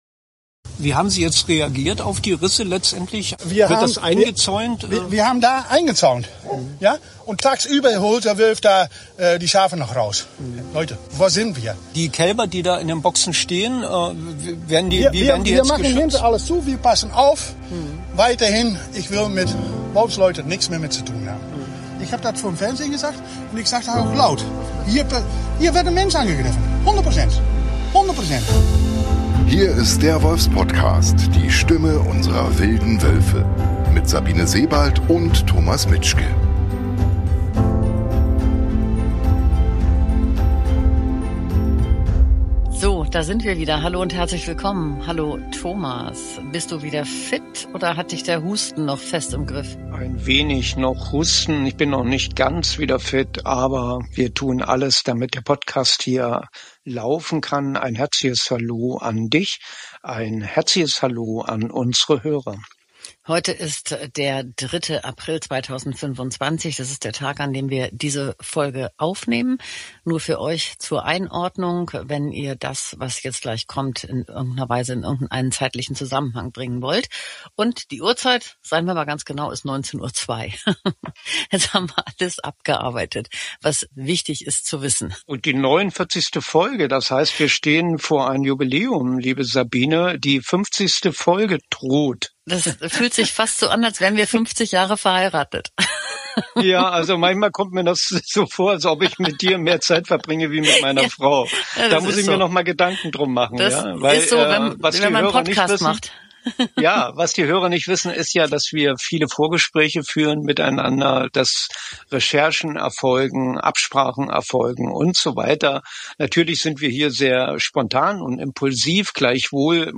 Den Besuch, aus Archivmaterial zusammen geschnitten, hört ihr hier nochmal in voller Länge. Im niedersächsischen Celle wurde am 28. März eine illegal getötete Wölfin schwimmend in der Aller gefunden.